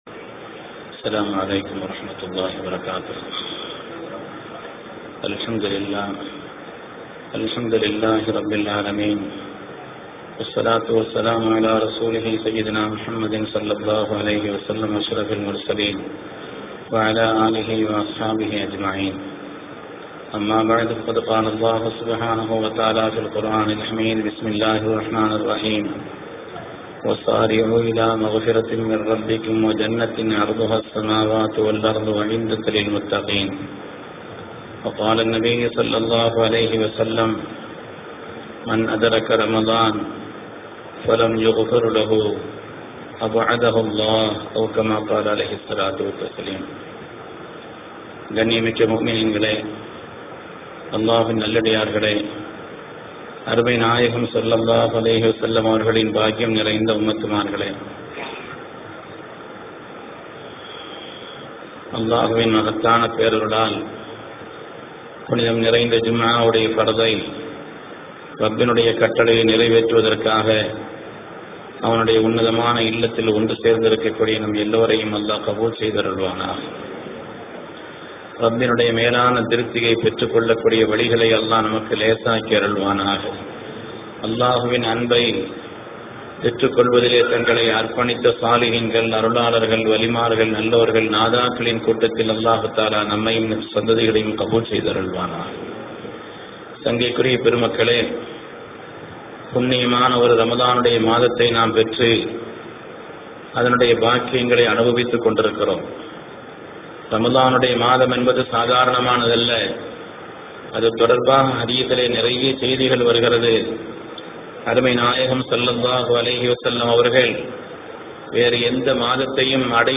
Jumma_Thouba_in_Ramadan_TIA_S1387.mp3